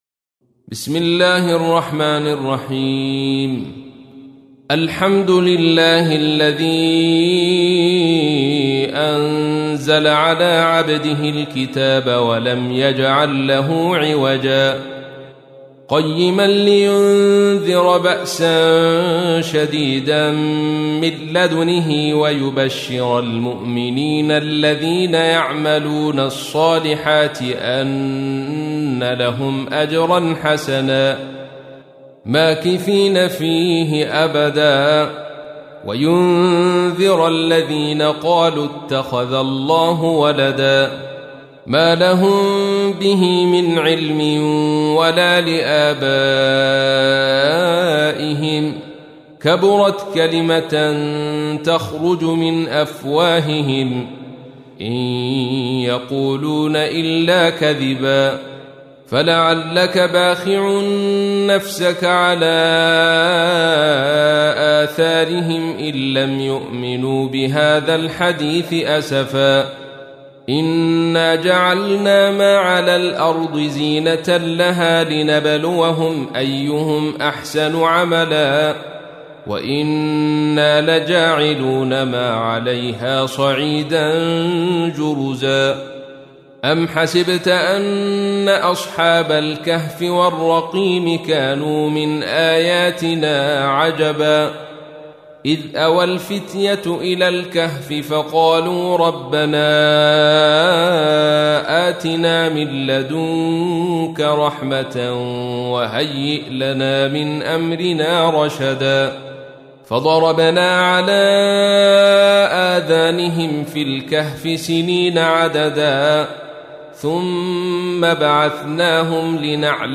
تحميل : 18. سورة الكهف / القارئ عبد الرشيد صوفي / القرآن الكريم / موقع يا حسين